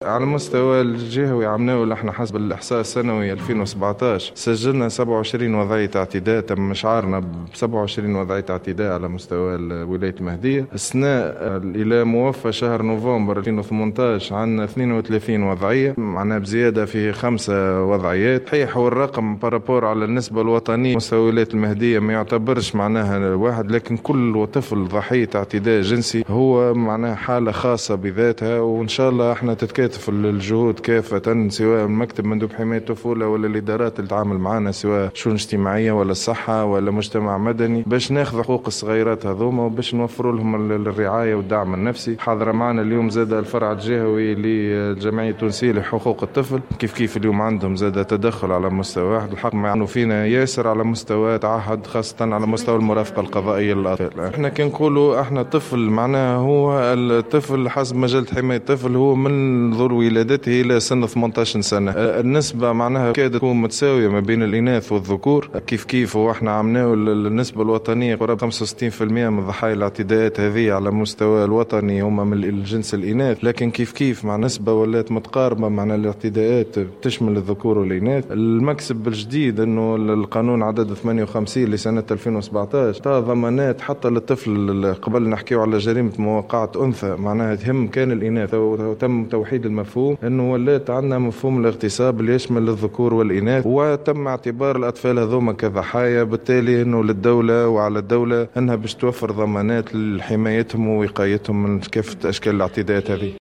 وأضاف في تصريح لمراسل الجوهرة اف ام، خلال اليوم الجهوي التحسيسي لمناهضة الاعتداءات الجنسية المسلطة على الأطفال، أن الاعتداءات شملت الذكور والإناث، مشيرا إلى أن العائلة تعتبر أكثر مكان يتعرض فيه الأطفال للعنف.